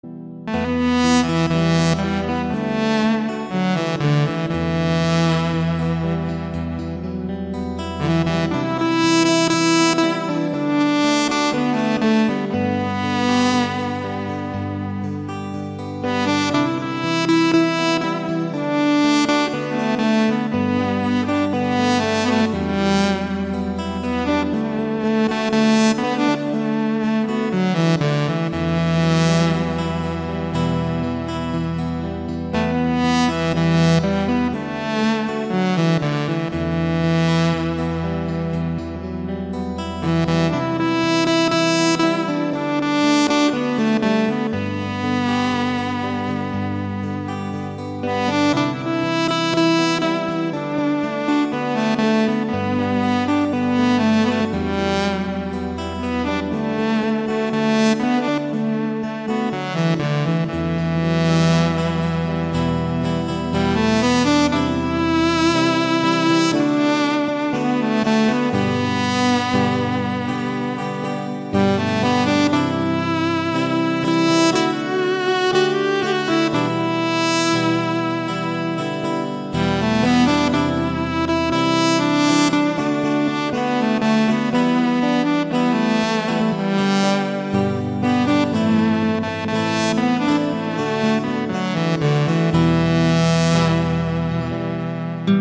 giai điệu hiện đại và sâu lắng.